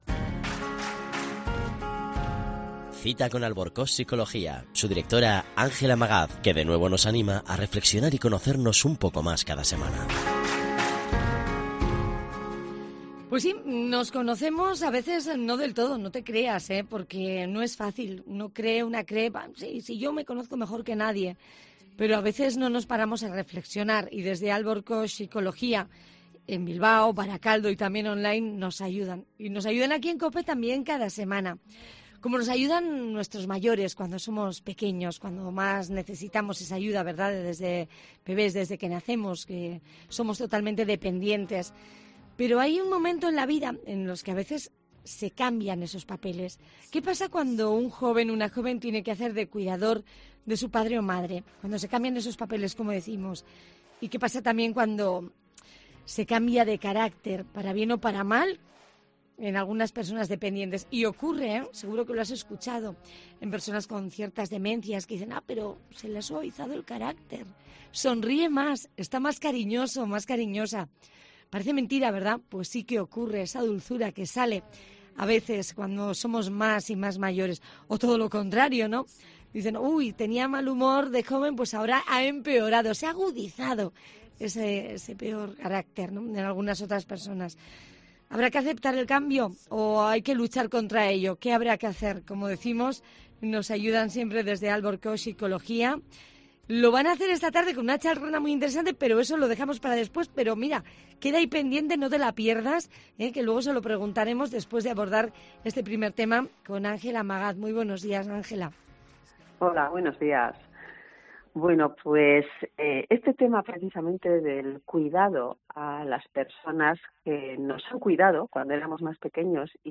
Acompáñanos en esta entrevista sobre la complejidad emocional y el proceso de ajuste que enfrentan aquellos jóvenes que asumen el papel de cuidadores en sus familias.